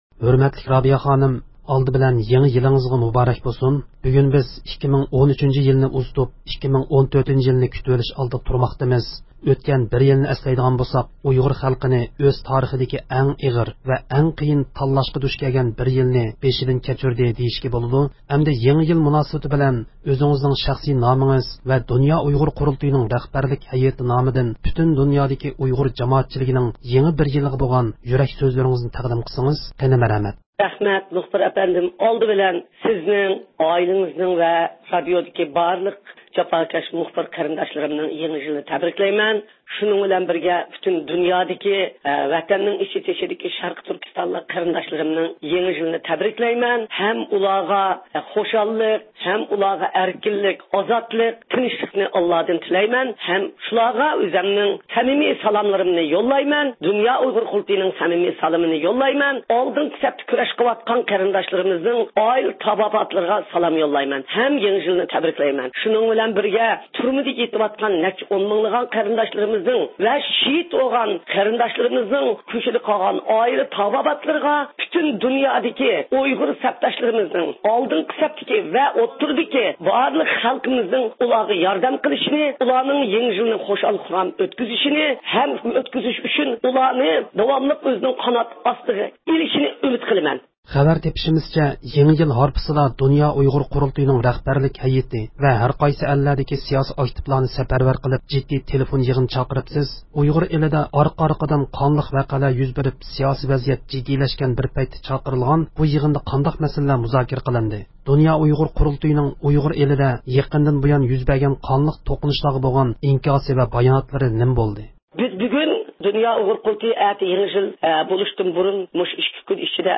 2014-يىلى كىرىش مۇناسىۋىتى بىلەن ئۇيغۇر مىللىي ھەرىكىتىنىڭ رەھبىرى، دۇنيا ئۇيغۇر قۇرۇلتىيىنىڭ رەئىسى رابىيە قادىر خانىم پۈتۈن دۇنيادىكى ئۇيغۇر خەلقىگە يېڭى يىللىق سالام يوللىدى.